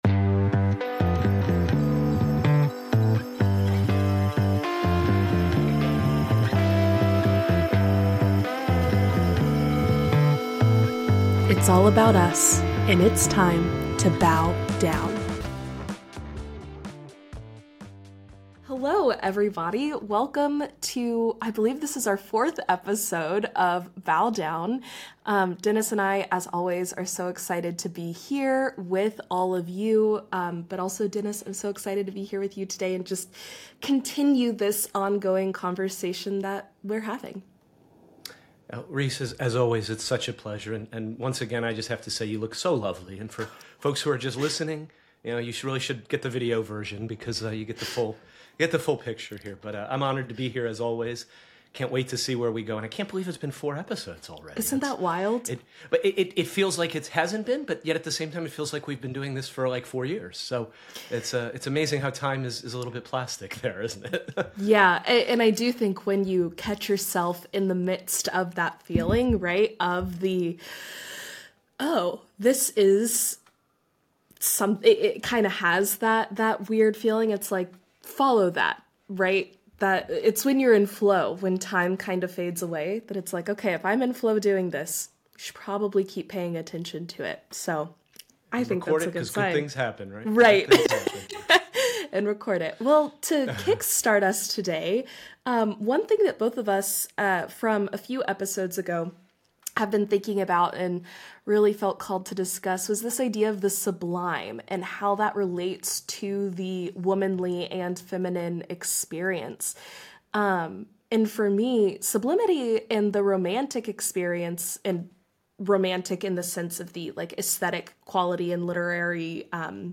conversations ranging from personal narratives to philosophical manifestos